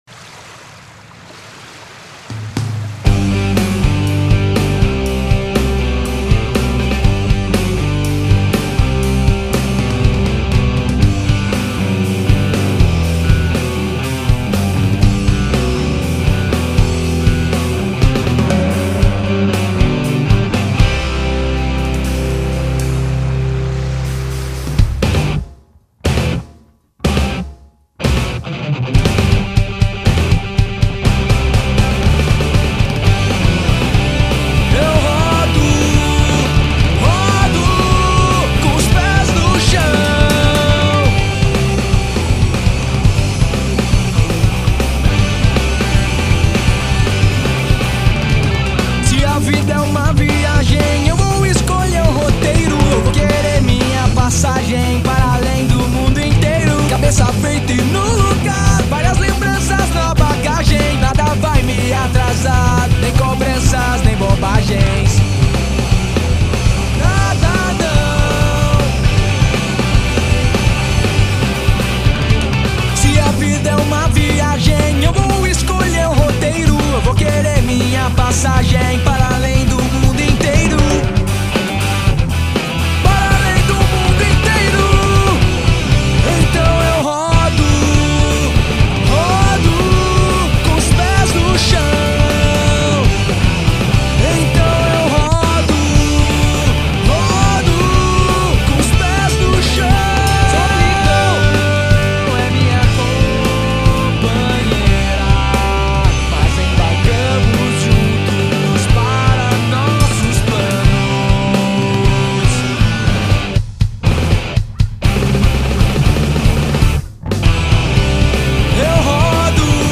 EstiloHardcore